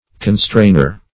Constrainer \Con*strain"er\, n. One who constrains.